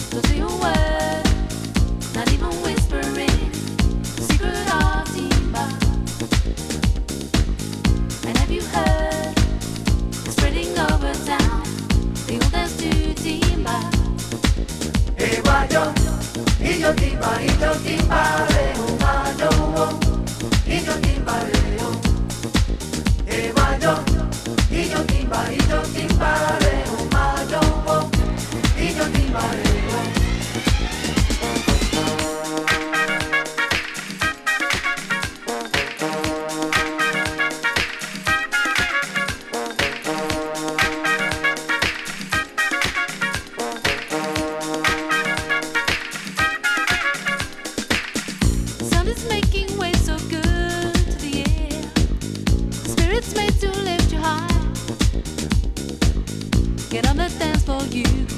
末永くPlay出来そうな、飽きの来ないサウンドですね。